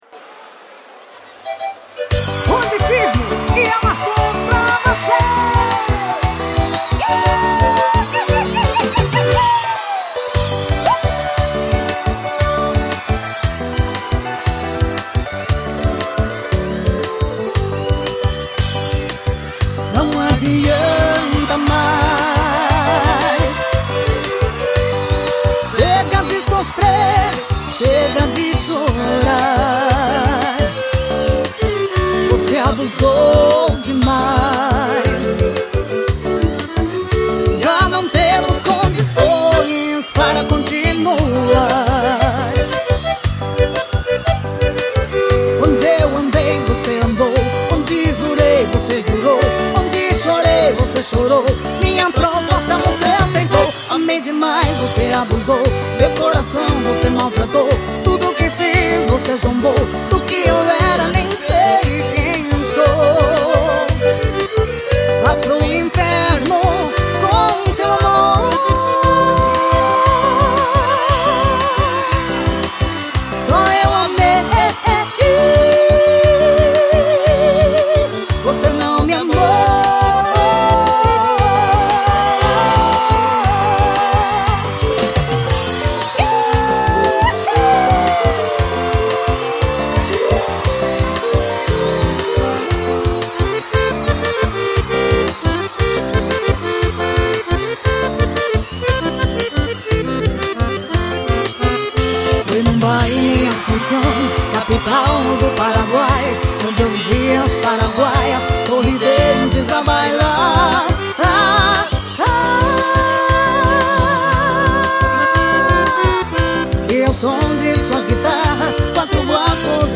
Composição: Polca.